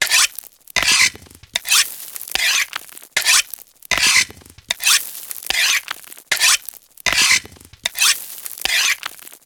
sharpen.ogg